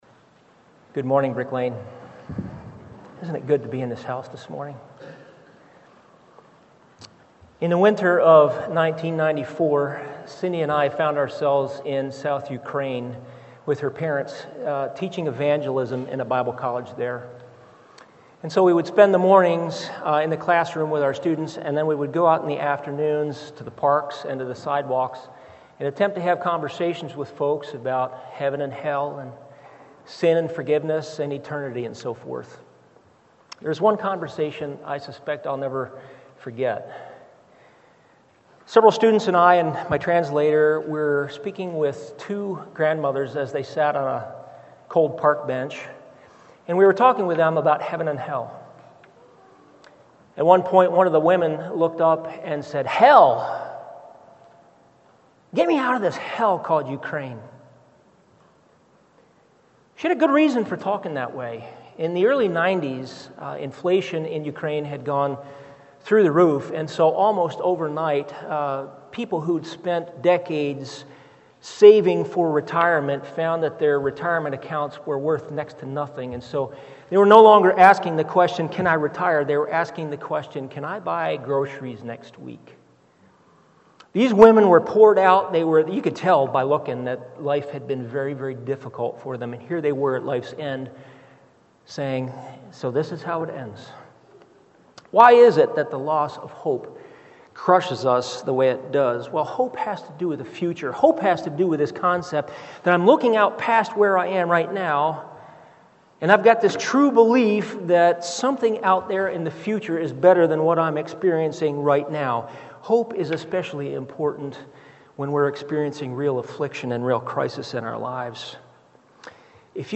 Sermons on Romans 5:1-5 — Audio Sermons — Brick Lane Community Church